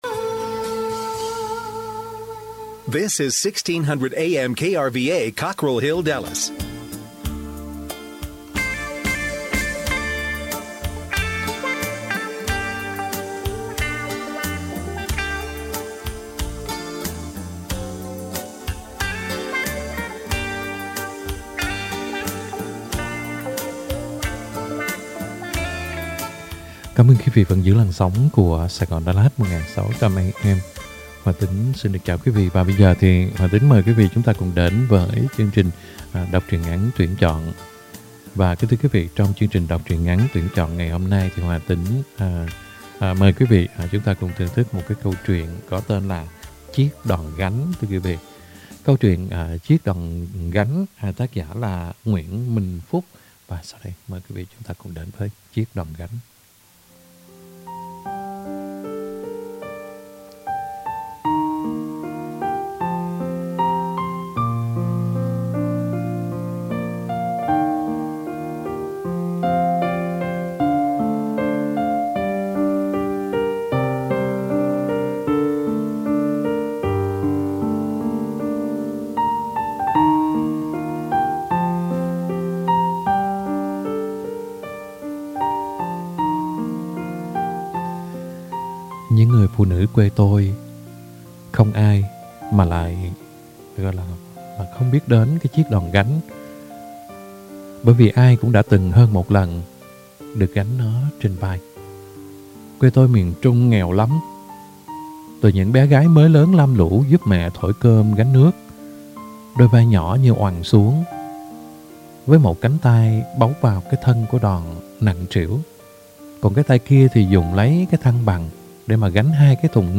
Đọc Truyện Ngắn = Chiếc Đòn Gánh - 12/14/2021 . | Radio Saigon Dallas - KBDT 1160 AM